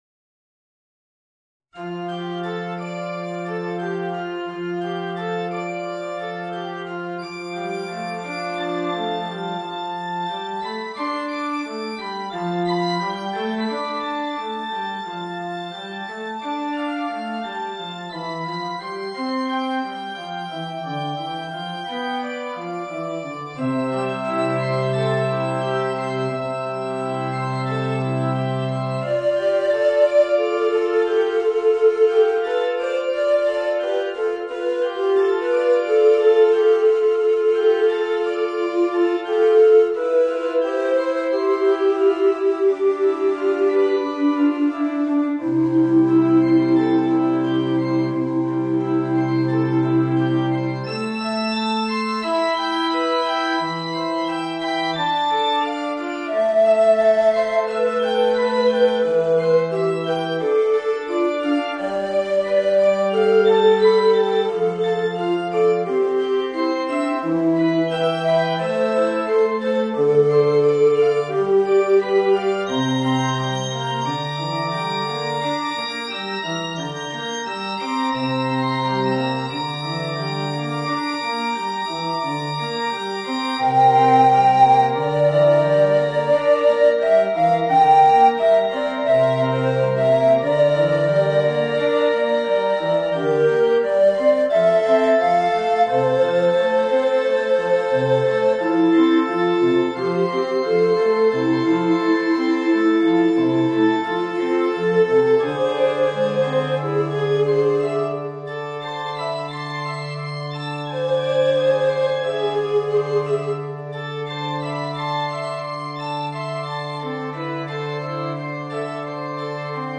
Voicing: Tenor Recorder and Organ